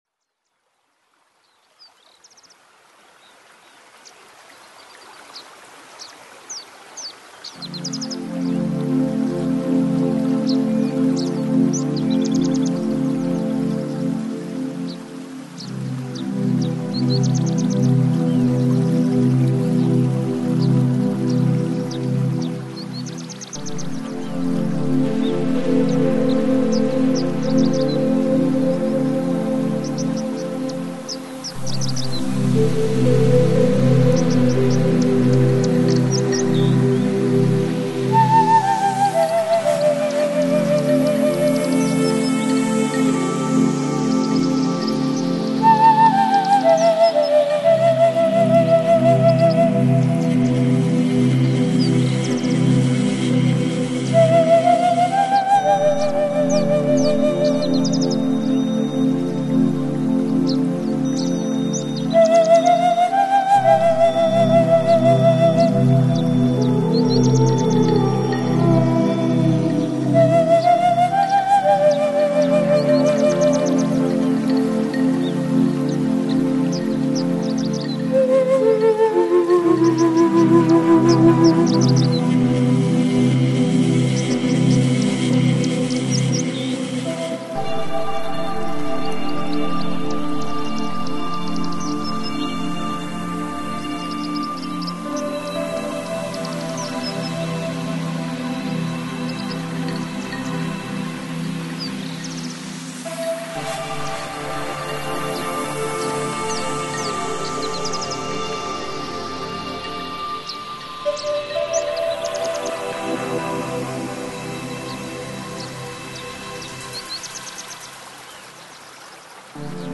Жанр: Chill Out, Lounge, Downtempo